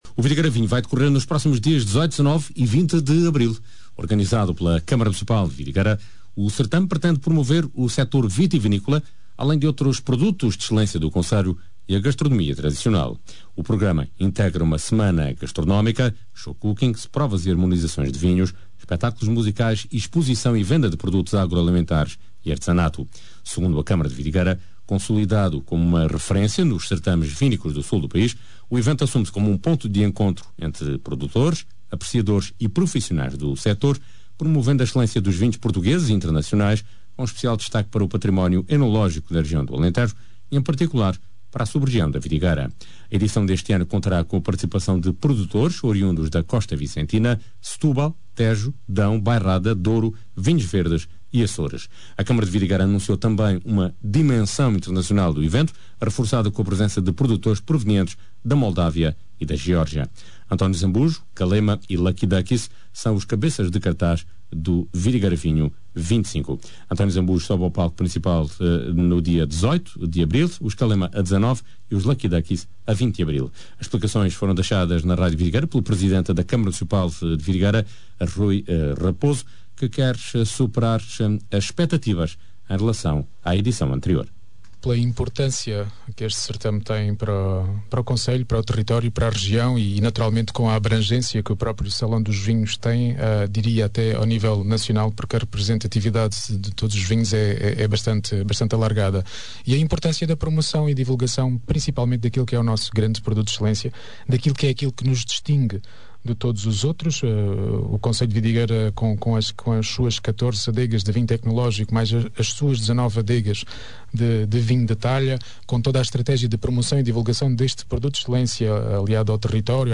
pode ouvir em baixo a entrevista ao presidente da Cãmara Municipal de Vidigueira Rui Raposo